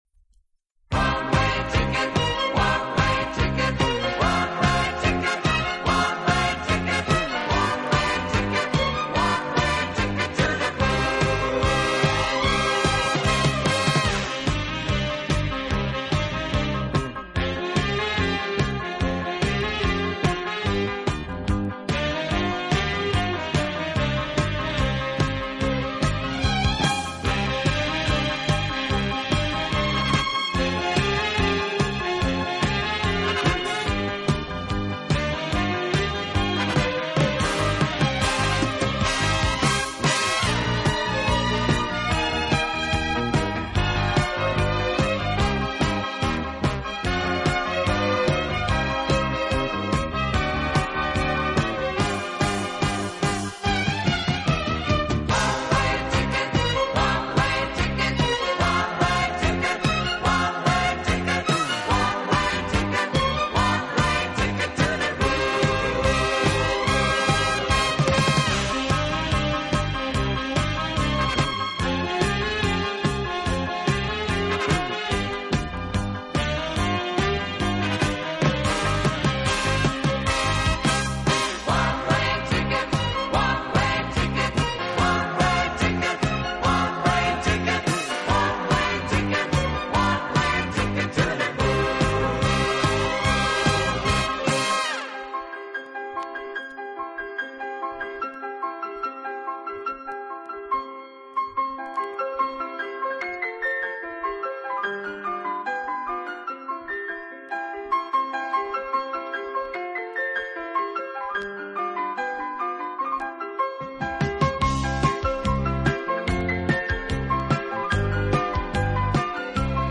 【轻音乐】
他的乐队以演奏舞